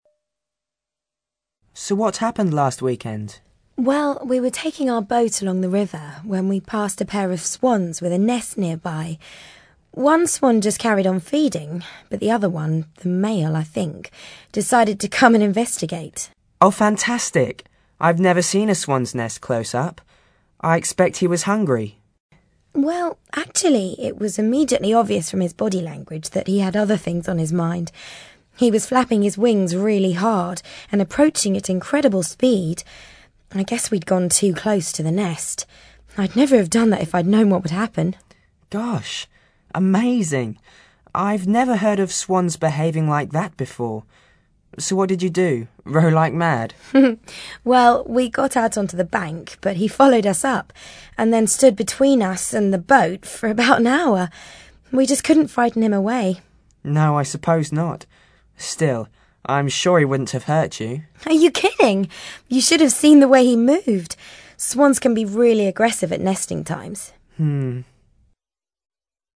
ACTIVITY 41: You are going to overhear a woman telling a friend a story about a swan.
What is the man's reaction to the story?